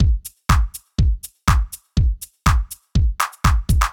ORG Beat - Mix 2.wav